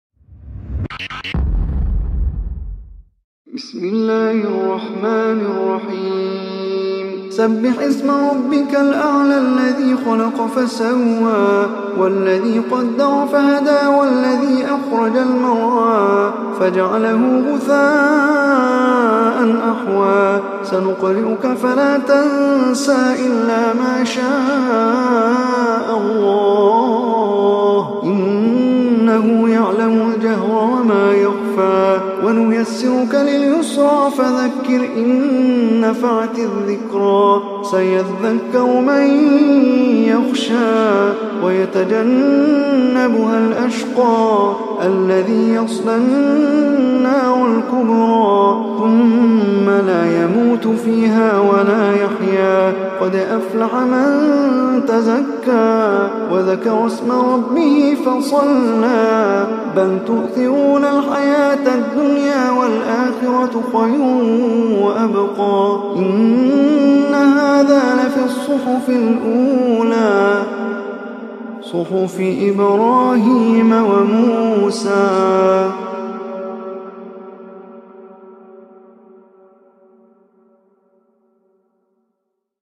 Surah Al Ala Recitation by Omar Hisham Arabi
Surah Al Ala, is 87 surah of holy Quran. Listen or play online m p3 tilawat / recitation in Arabic in the beautiful voice of Omar Hisham Al Arabi.